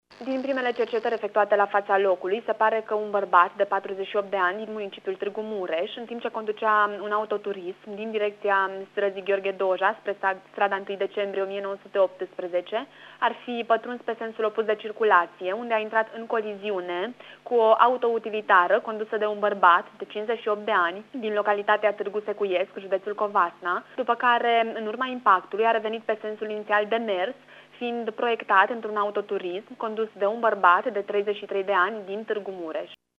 Accidentul s-ar fi produs după ce un autoturism a intrat pe sensul opus de circulație, spun polițiștii rutier.